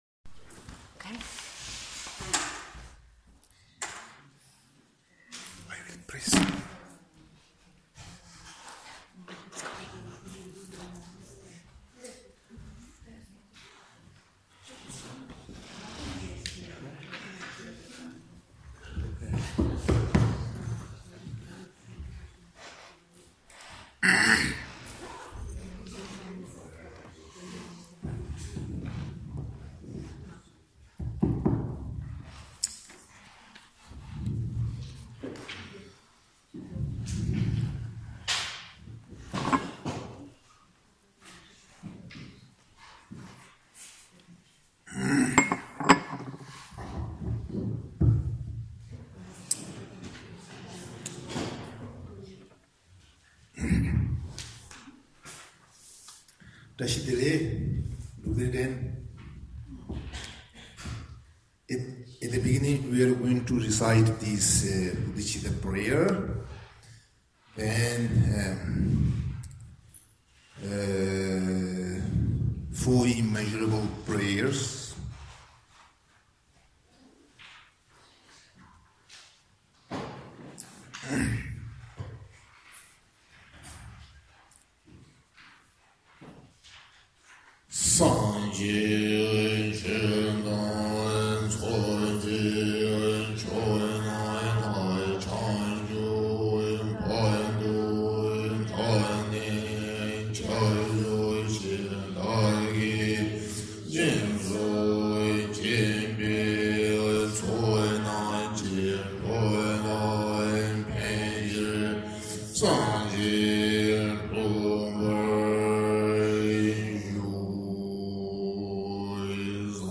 O květnovém úplňku jsme se v Tibet Open House poosmé sešli při recitaci manter za dlouhý život Jeho Svatosti dalajlámy.
mantrovc3a1nc3ad-guru-rinpoc48dhe.mp3